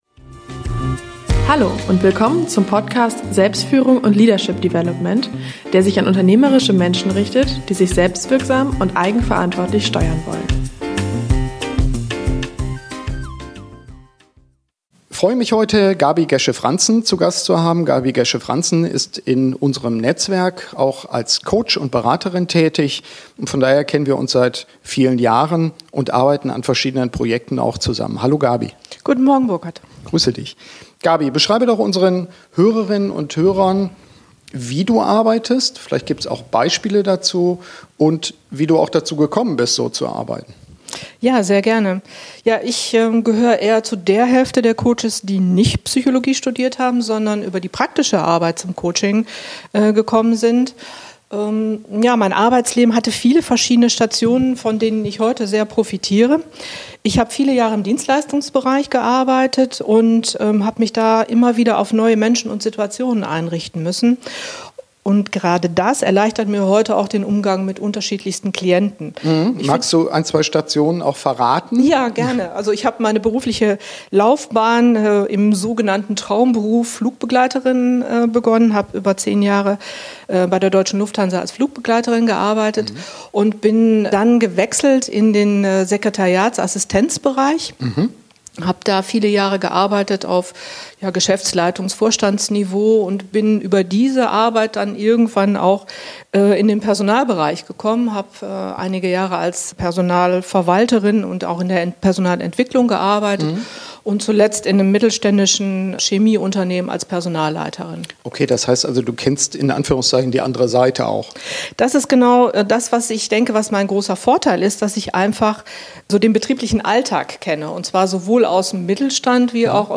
Mein heutiger Interviewgast ist Mitglied unseres Netzwerks.